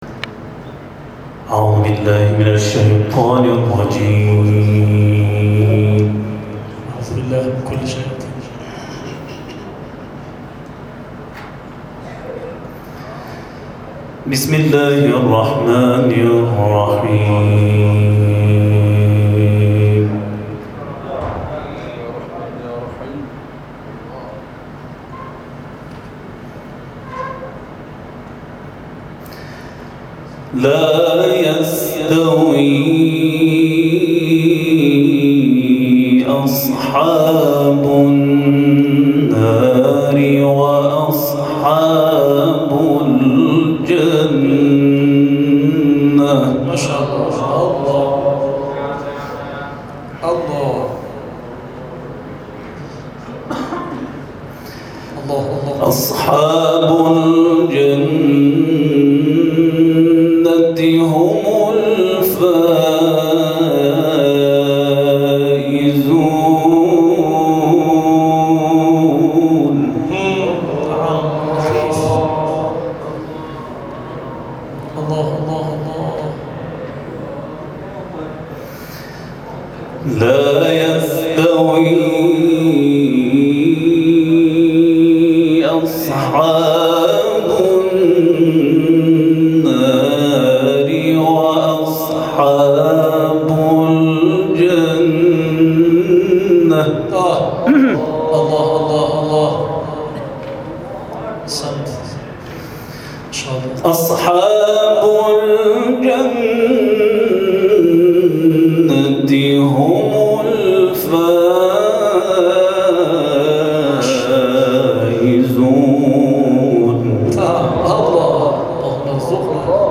مرحله مقدماتی دور سوم جشنواره تلاوت‌های مجلسی پایان یافت + صوت و عکس
تلاوت